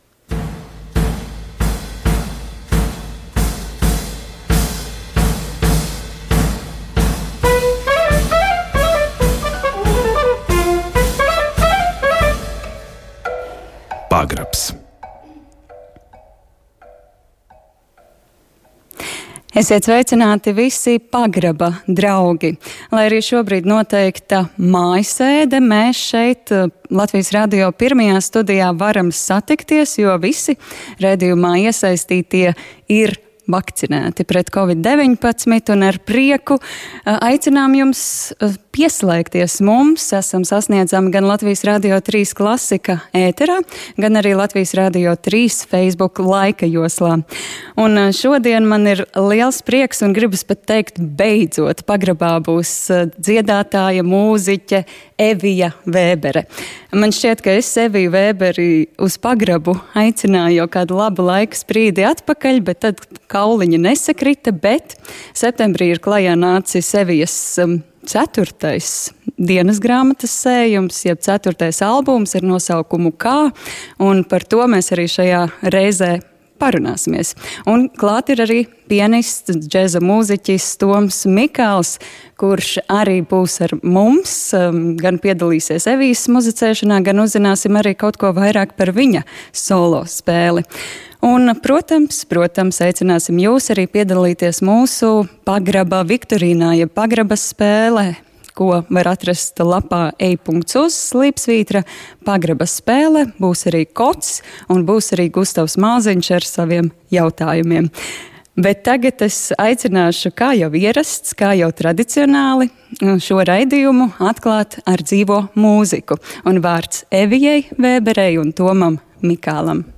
Latvijas Radio 1. studijā